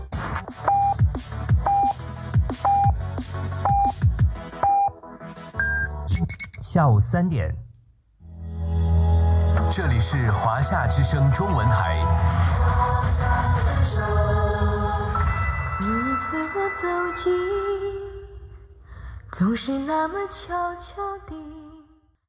Identificació en llengua mandarí i música
Banda DAB